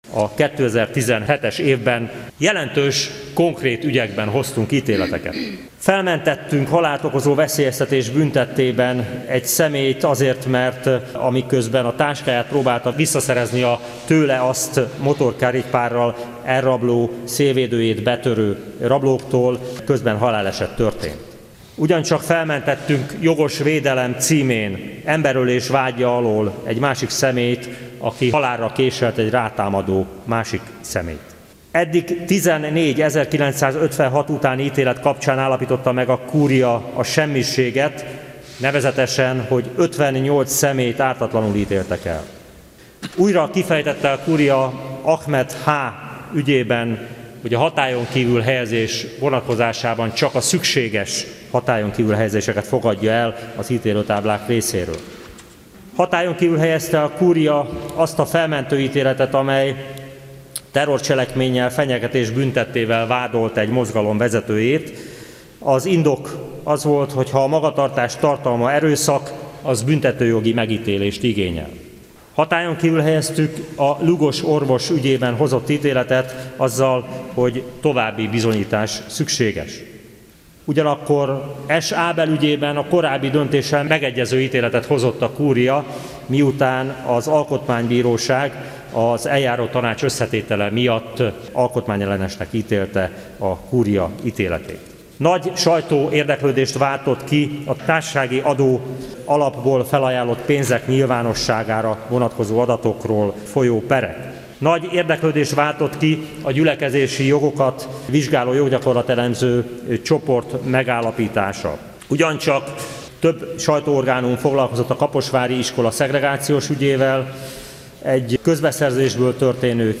A Kúria 2018. évi első Teljes Üléséről számolt be az InfoRádió 2018. március 1-én. A „Paragrafus” című jogi magazinműsorban hallható volt dr. Darák Péter, a Kúria elnökének köszöntő beszéde.